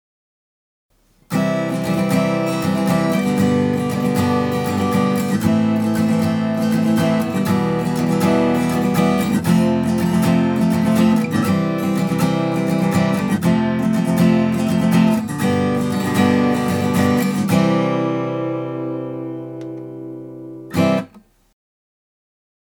厚いピック
厚いピック：1.2mm
厚いピックはなんというか暖かみがあるというか。
割と低音域がでてる感じなのでやわらかい音がしてますね。